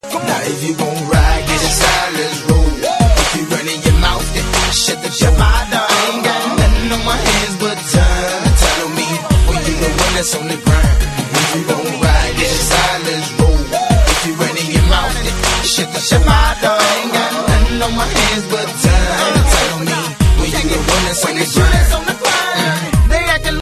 • Category Hip Hop